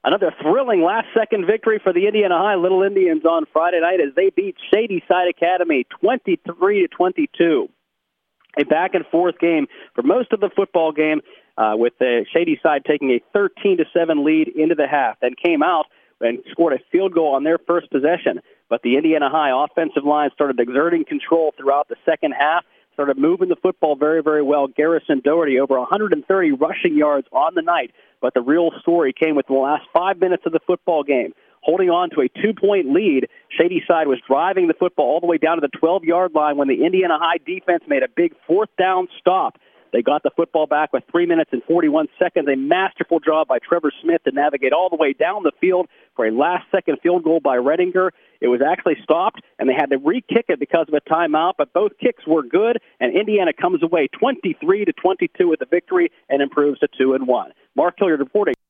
recap on WDAD FM 100.3 and AM 1450.
hsfb-indiana-vs-shady-side-academy-recap.mp3